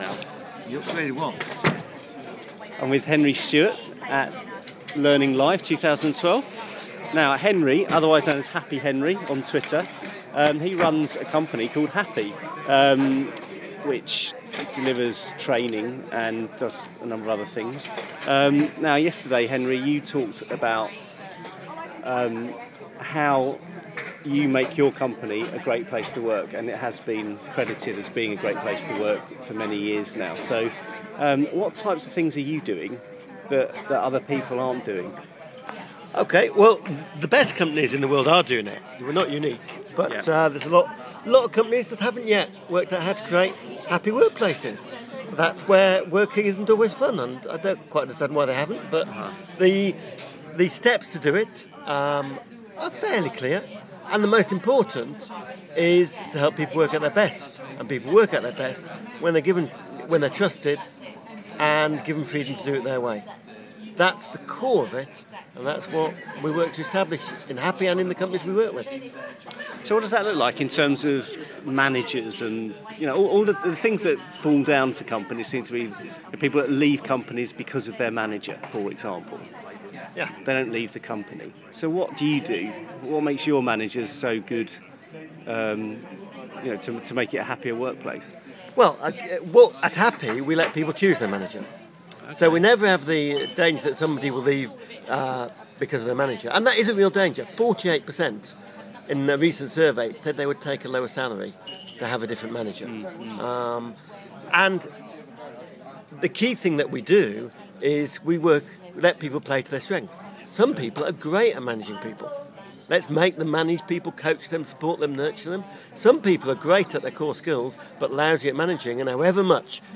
at the Learning Live 2012 conference.